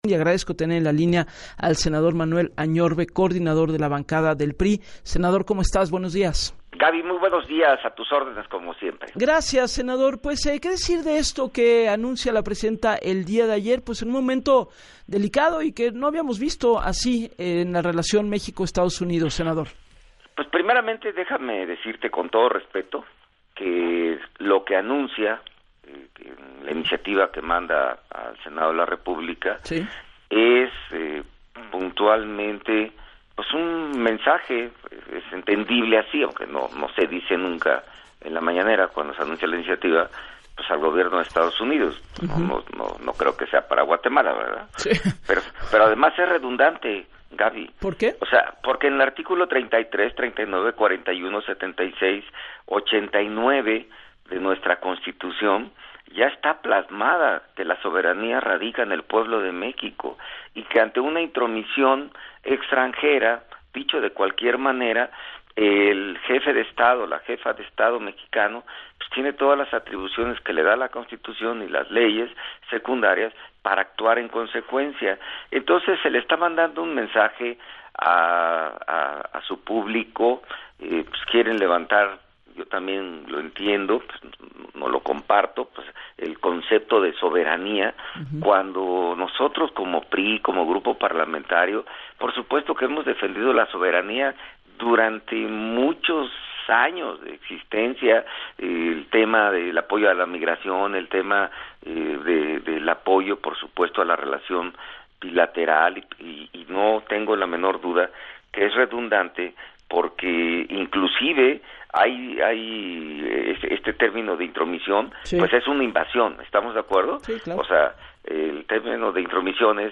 Sin embargo, recalcó en entrevista para “Así las Cosas” con Gabriela Warkentin, que “en leyes secundarias y código federal penal va más allá, se habla de golpe de Estado, me brinca este tema”.